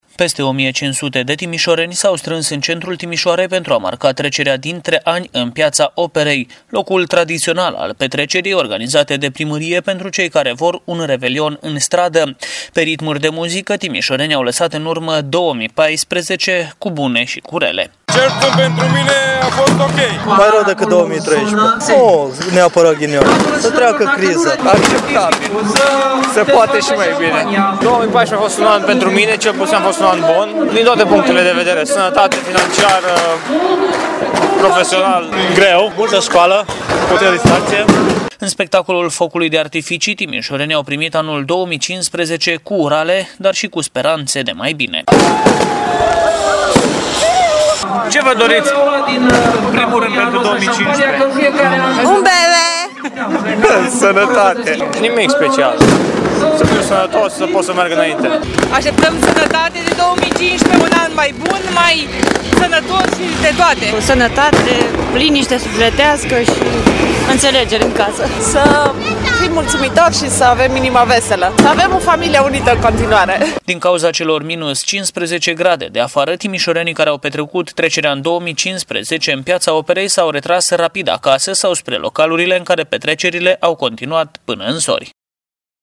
Pe ritmuri de muzică, timișorenii au lăsat în urmă anul 2014, cu bune sau cu rele.
Trecerea în noul an a fost marcată de un foc de artificii, iar timișorenii l-au primit pe 2015 cu urale dar și cu speranțe de mai bine.